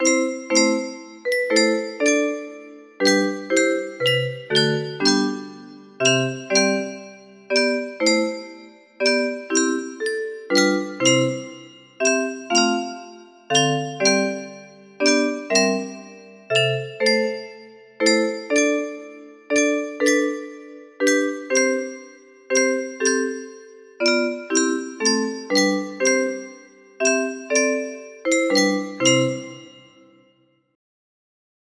O Little One Sweet, O little One Mild music box melody
German melody, setting by J.S. Bach